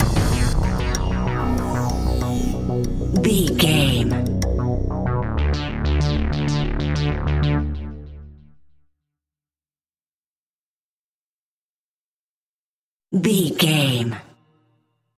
Aeolian/Minor
scary
ominous
haunting
eerie
industrial
cello
synthesiser
percussion
drums
horror music